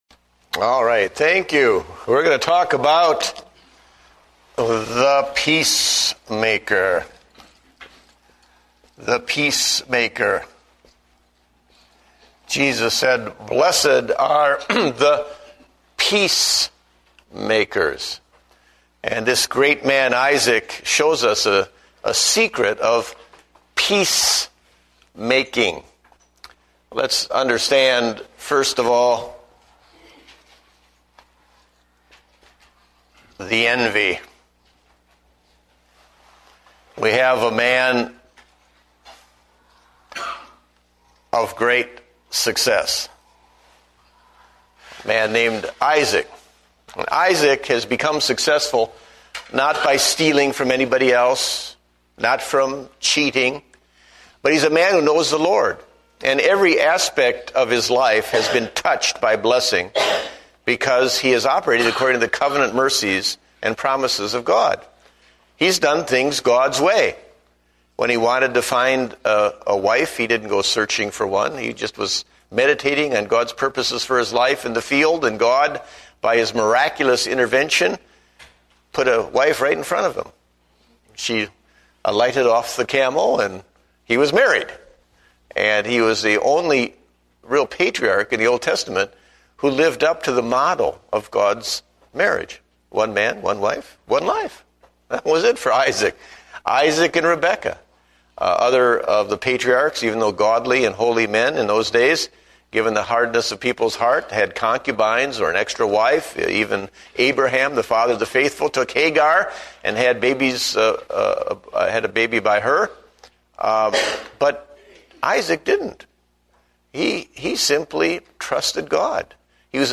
Date: March 22, 2009 (Adult Sunday School)